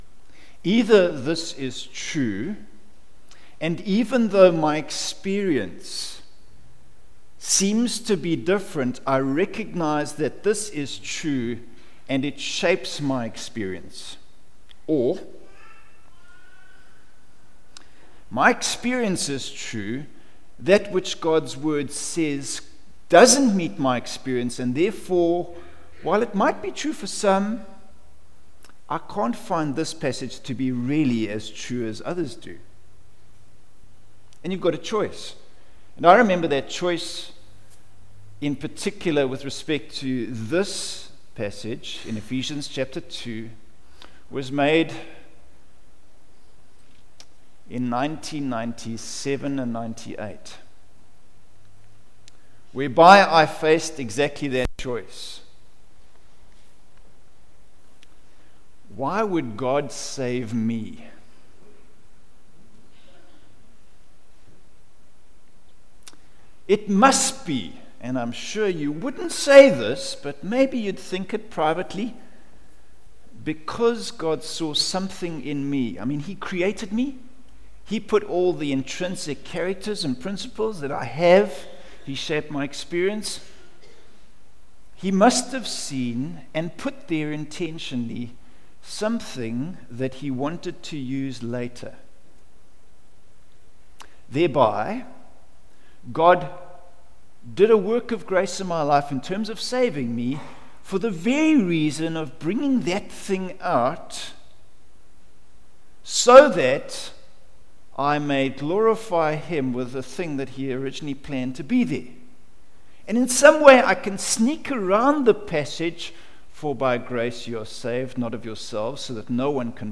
Facebook Twitter email Posted in Morning Service